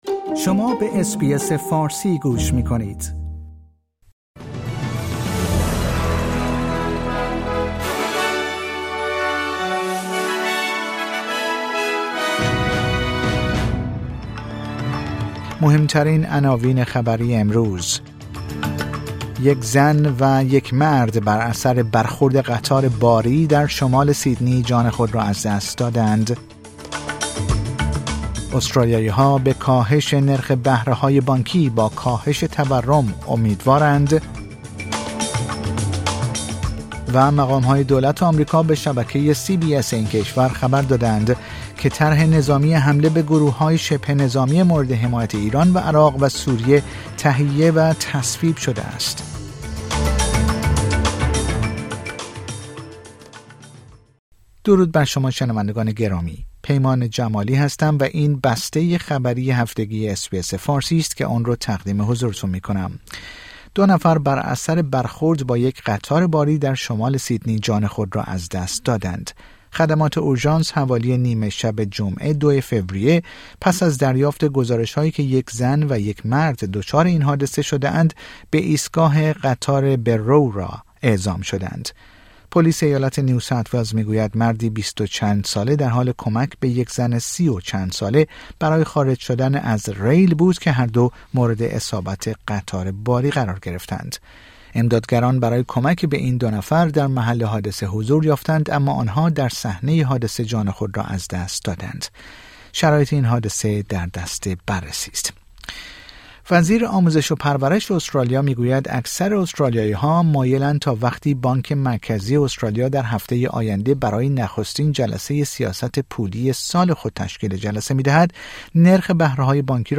در این پادکست خبری مهمترین اخبار استرالیا، جهان و ایران در یک هفته منتهی به شنبه سوم فوریه ۲۰۲۴ ارائه شده است.